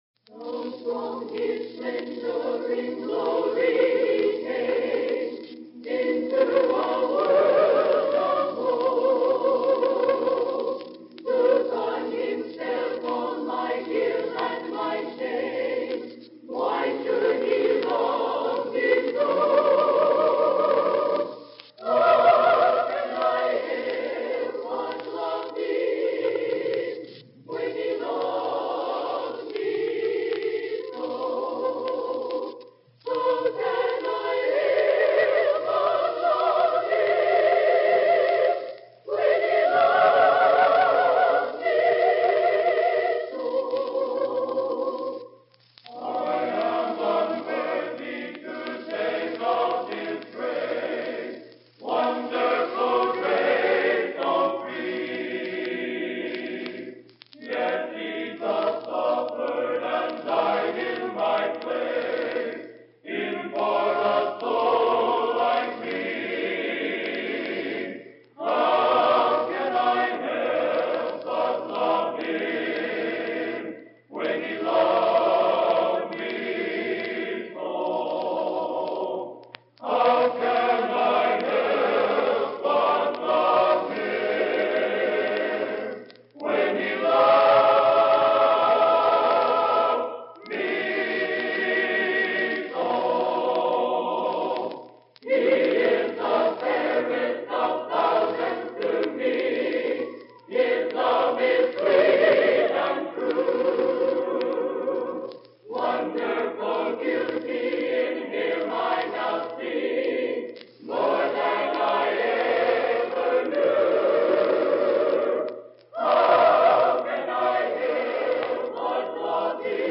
This is a direct-to-disc recording of the Bethany Nazarene College A Cappella Choir Annual Tour from 1962-1963 year.